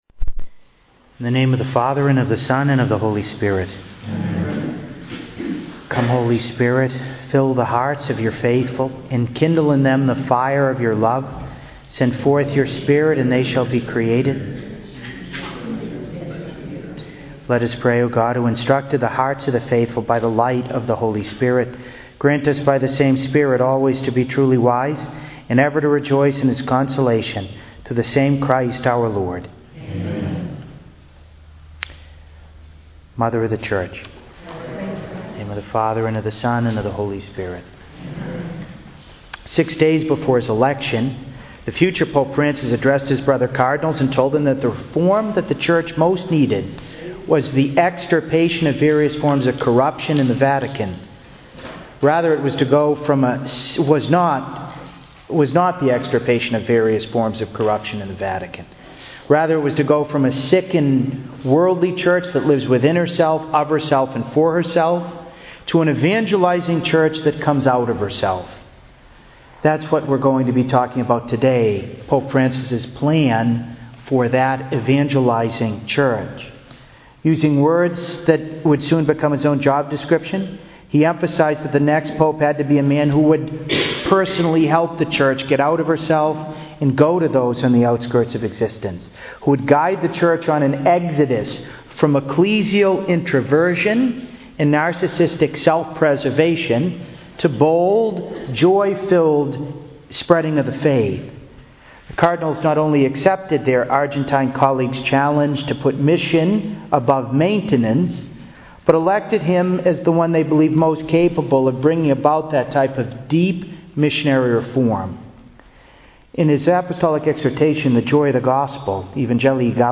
To listen to an audio recording of this conference, please click below: